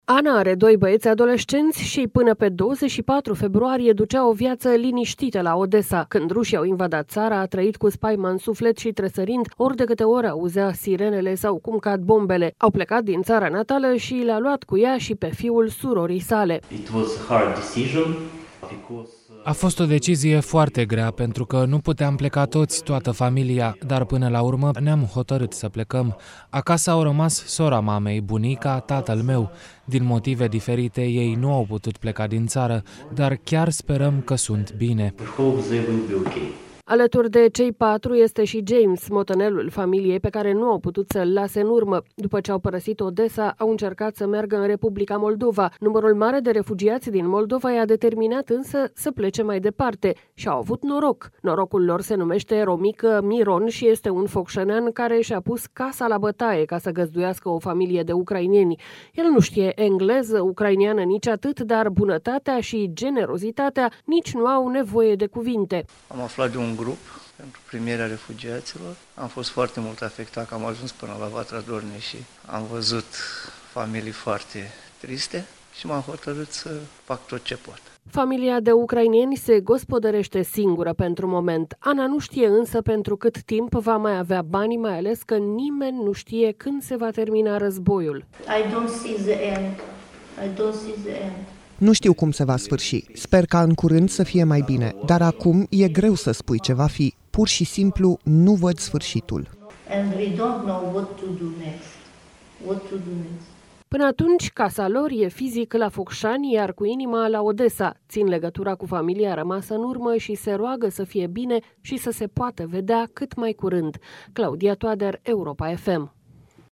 Reportaj: Bărbatul care nu știe ucraineană, dar a primit în casă mai mulți refugiați.